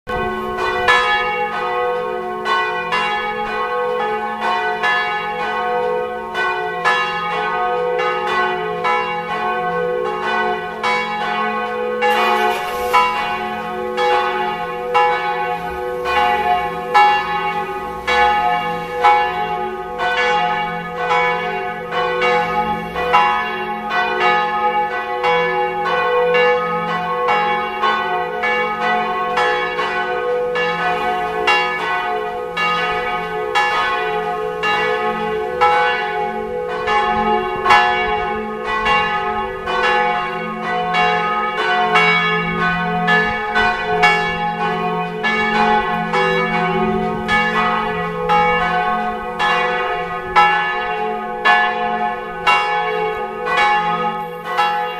Categoria Effetti Sonori